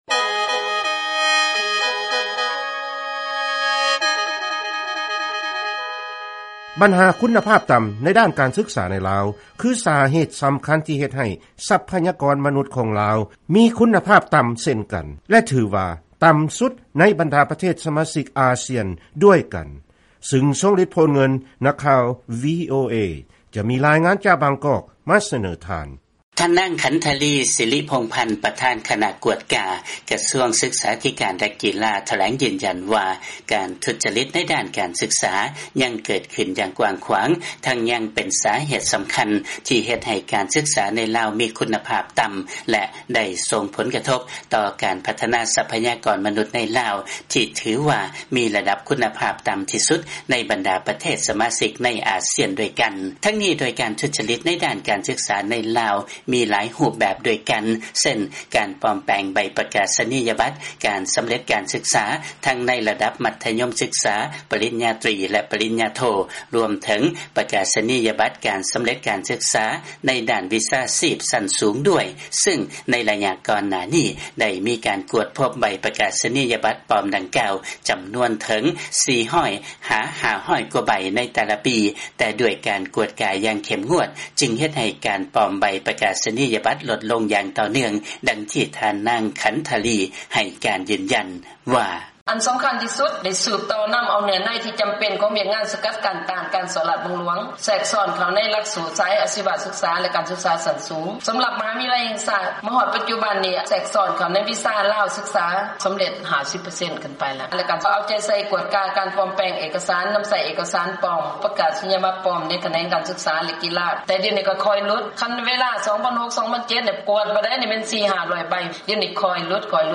ຟັງລາຍງານ ລາວມີຊັບພະຍາກອນ ມະນຸດ ຄຸນນະພາບຕໍ່າ ທີ່ສຸດ ໃນອາຊຽນ